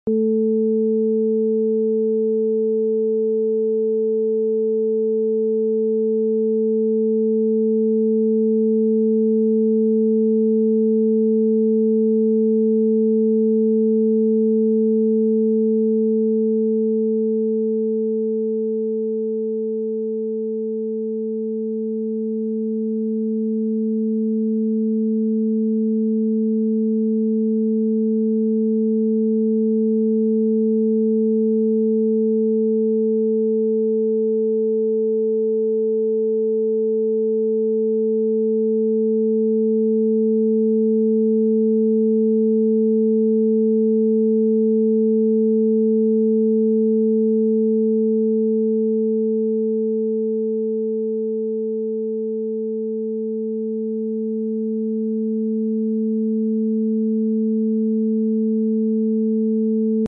Frequência Binaural 440hz #440hz sound effects free download